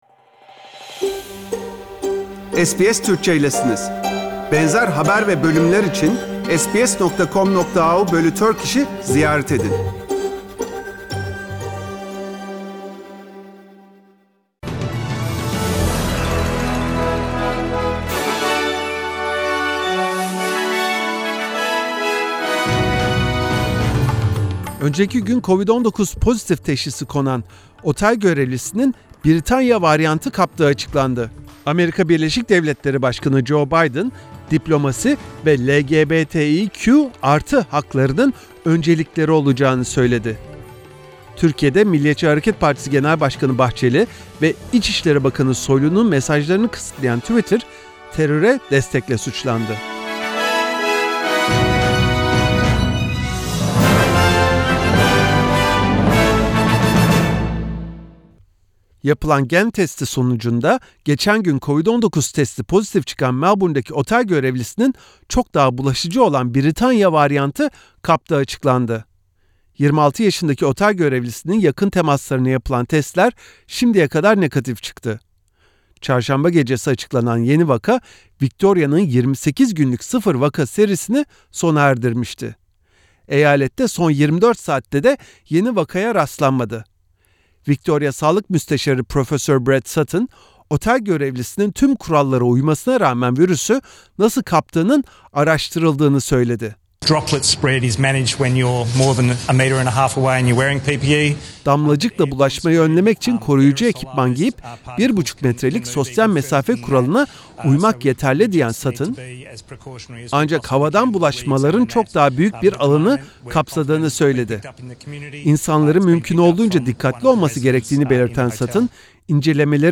SBS Türkçe Haberler 5 Şubat